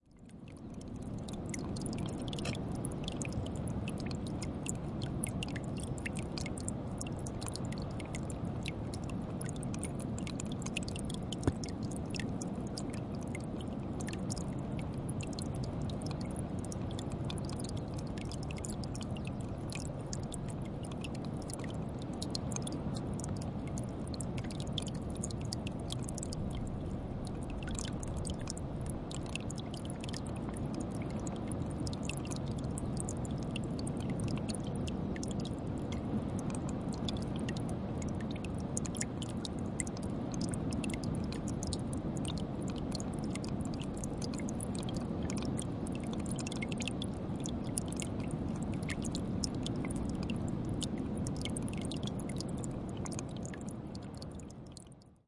纳什角的水记录" 涓涓细流进入岩石池，背景是冲浪，格兰摩根海岸，威尔士
描述：在纳什点岩石侏罗纪海滩上的岩石流入池中的一小滴水。重的海浪在背景中。记录缩放H2N
标签： 涓流 岩石池 现场录音 速度慢 冲浪
声道立体声